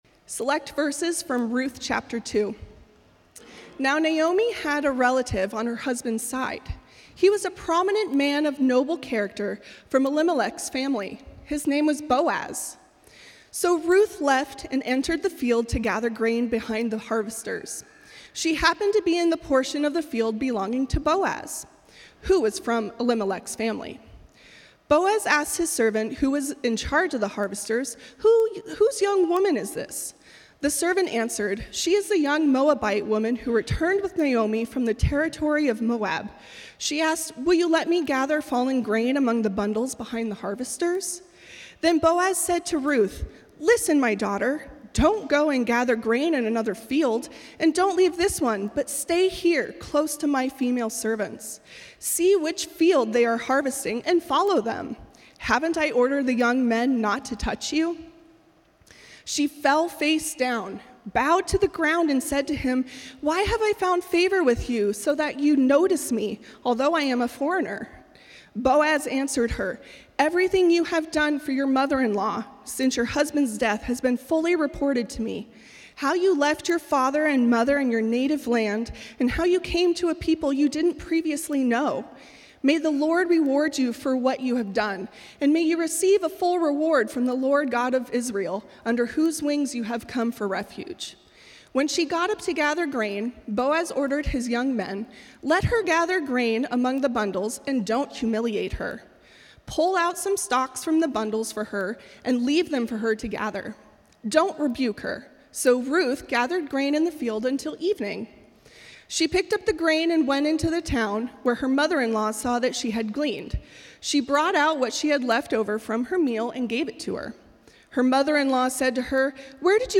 Join us each week as we dive deep into the Word of God and explore the life-transforming message of Jesus and the grace He offers us. In each episode, we bring you the dynamic and inspiring Sunday messages delivered by our passionate and knowledgeable pastors.